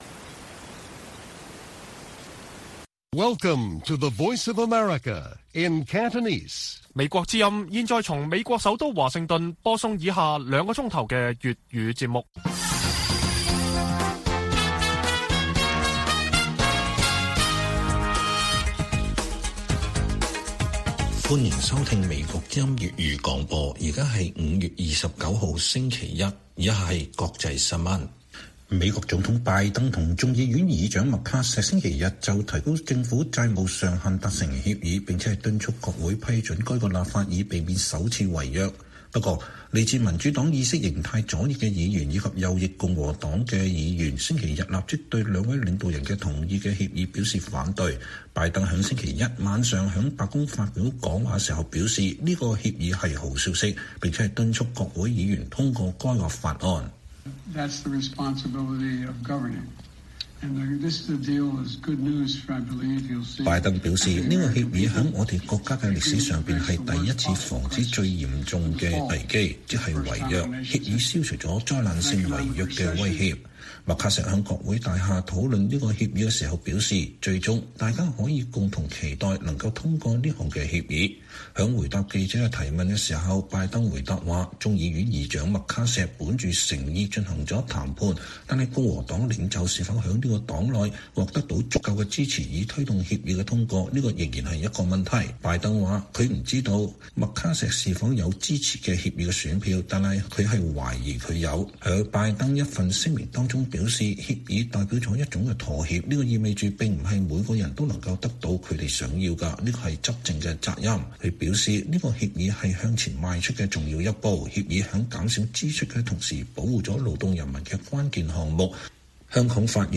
粵語新聞 晚上9-10點: 白宮與眾議院達成提高美國債務上限的協議